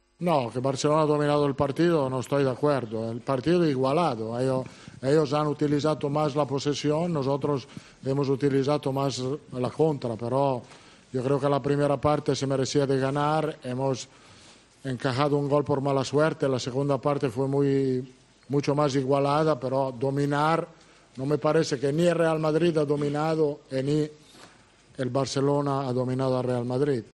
El entrenador del Real Madrid ha valorado la victoria de su equipo en rueda de prensa y, además, ha asegurado que "podría haber ganado cualquier equipo" este partido.